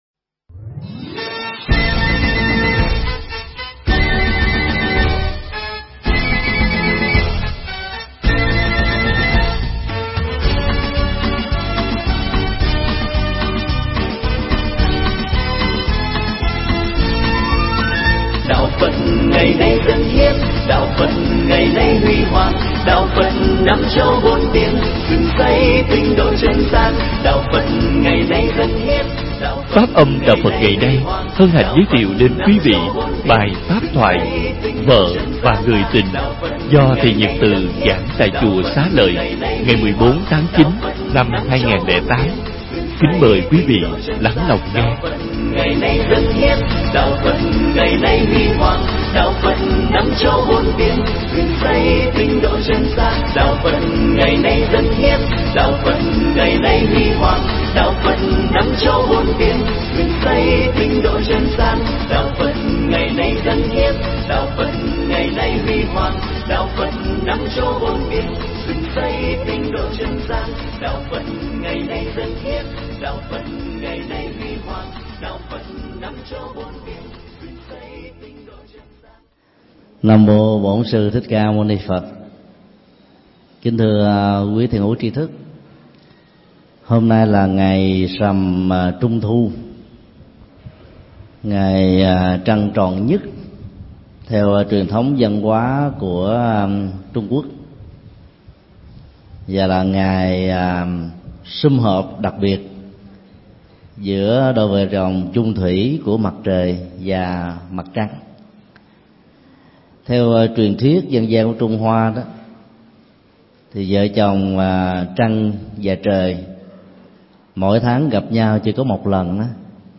Nghe mp3 thuyết pháp Vợ và người tình do Thầy Thích Nhật Từ giảng tại Chùa Xá Lợi, ngày 14 tháng 09 năm 2008.